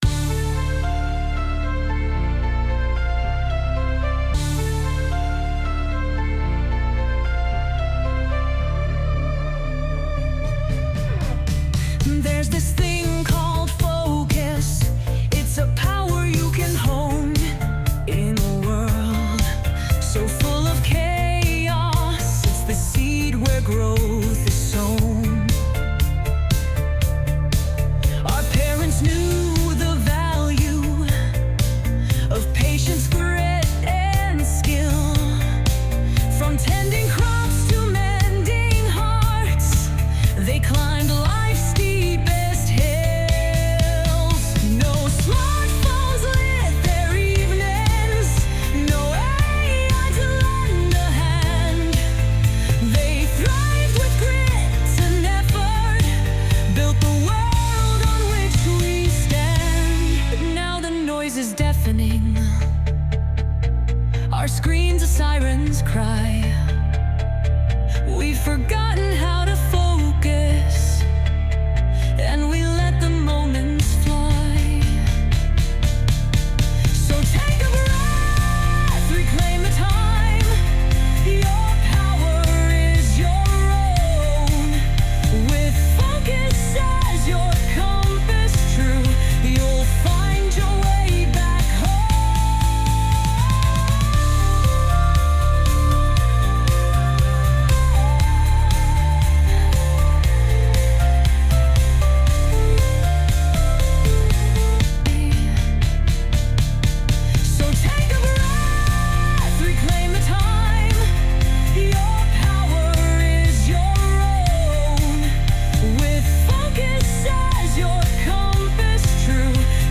Base music and vocals by MS Copilot and Ai Music Genrator.